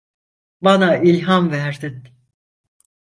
Pronunciat com a (IPA) /il.hɑm/